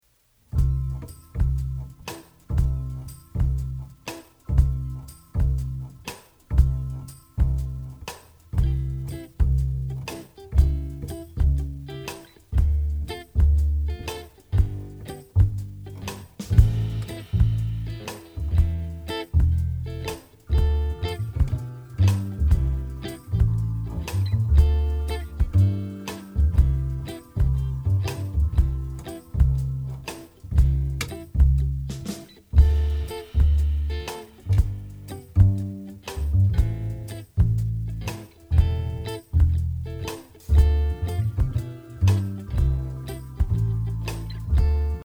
Listen to a sample of the instrumental track.